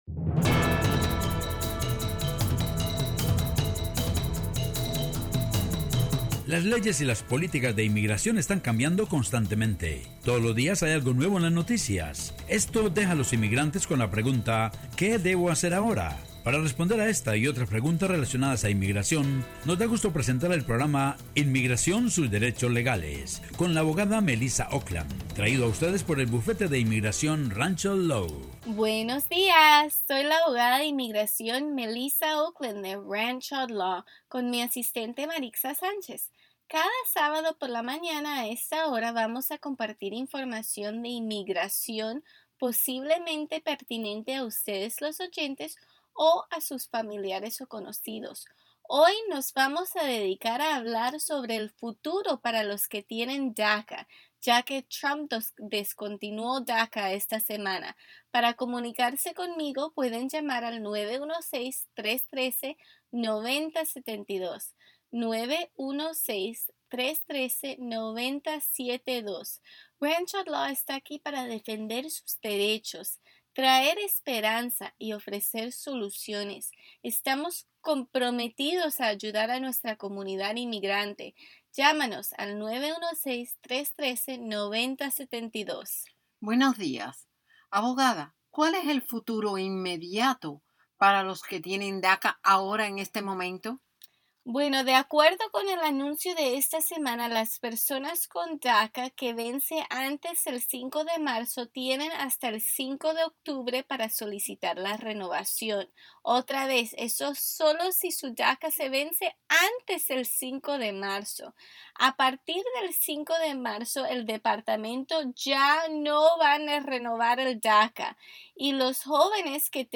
Abogada de inmigración explica la terminacion de DACA y opciones para el futuro